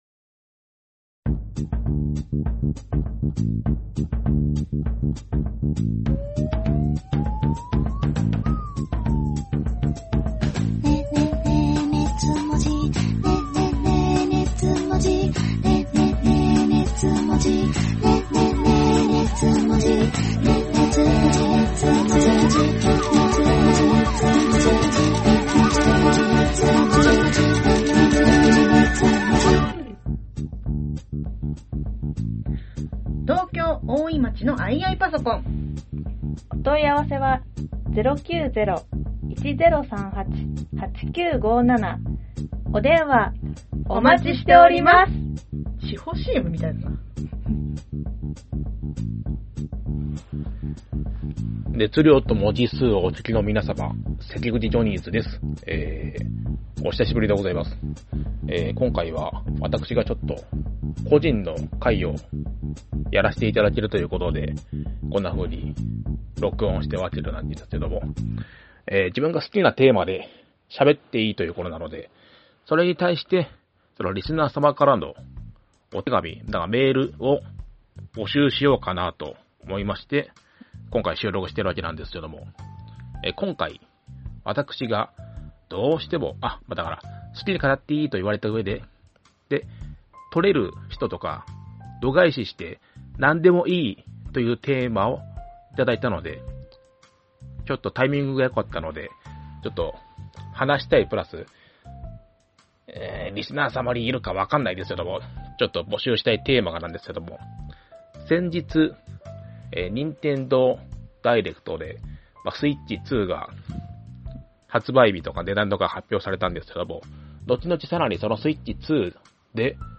オタク芸人 サンキュータツオ Presents 二次元を哲学するトークバラエティ音声マガジン『熱量と文字数』のブログです。